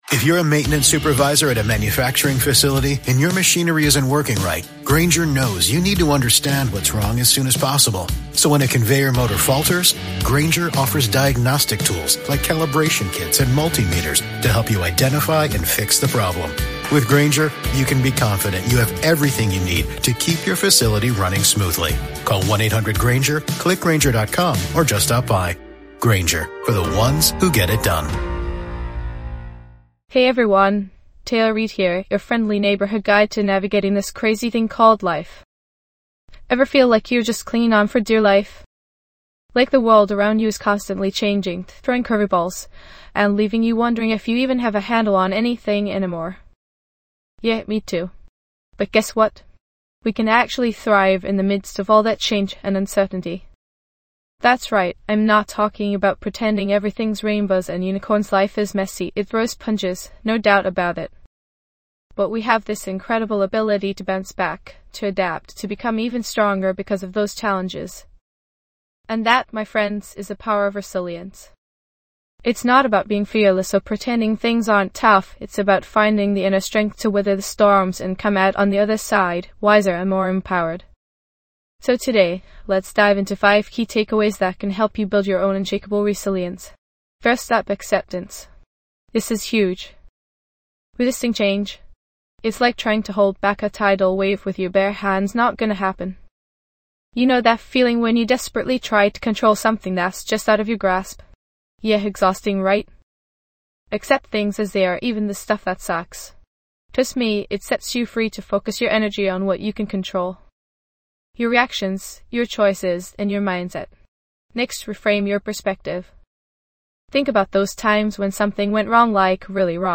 Self-improvement, Personal Development, Mindfulness, Inspirational Talks
This podcast is created with the help of advanced AI to deliver thoughtful affirmations and positive messages just for you.